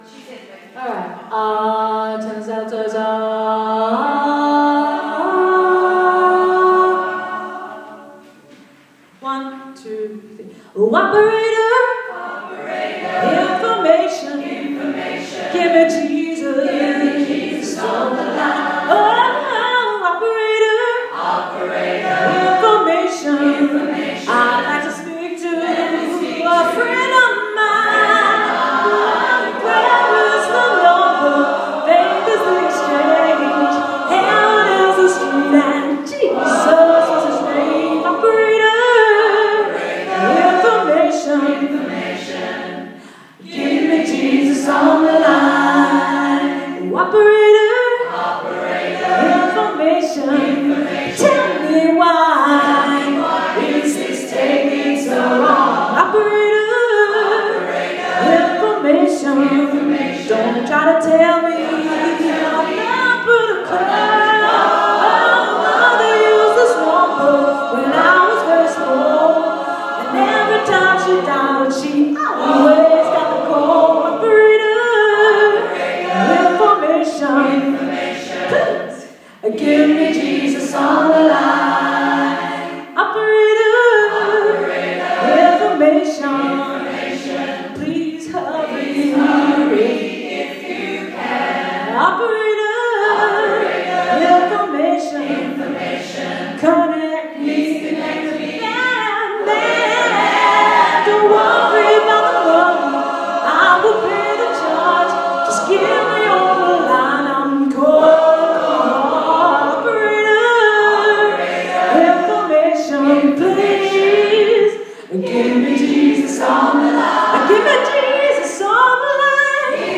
Operator as performed by Manhattan Transfer, this is an excerpt of a rehearsal April 2014